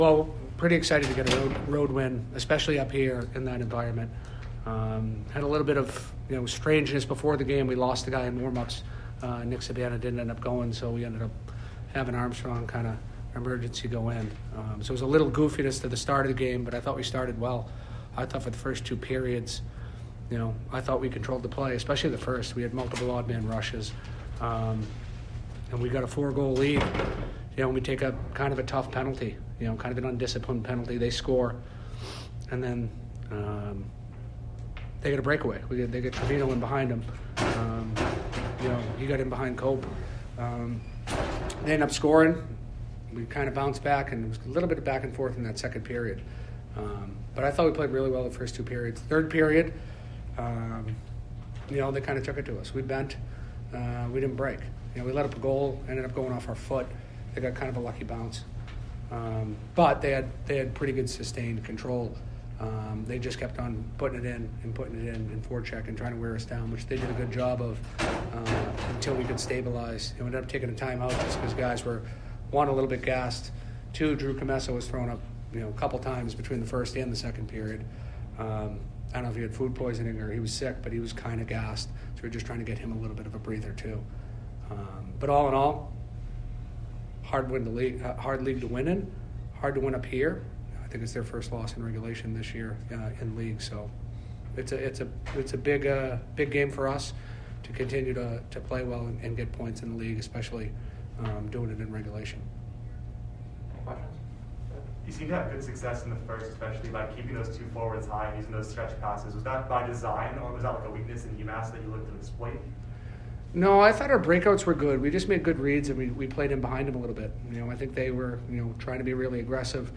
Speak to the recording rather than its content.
Postgame at UMass